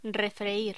Locución: Refreír
Sonidos: Voz humana